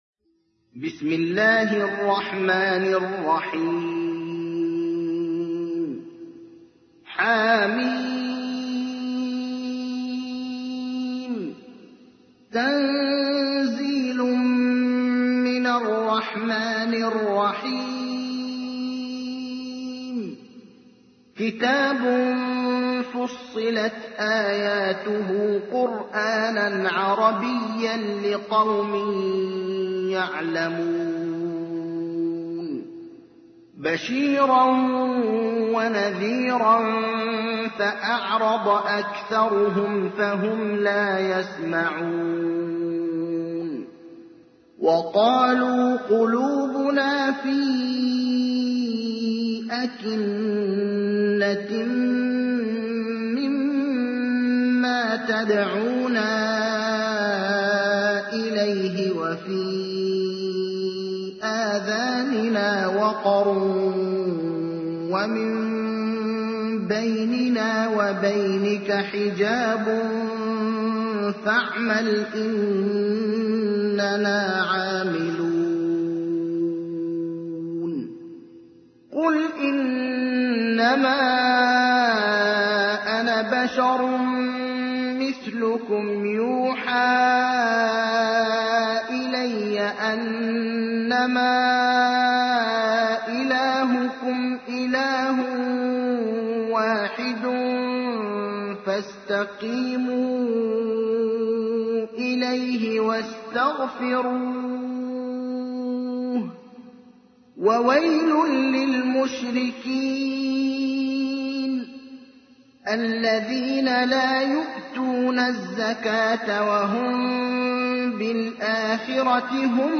تحميل : 41. سورة فصلت / القارئ ابراهيم الأخضر / القرآن الكريم / موقع يا حسين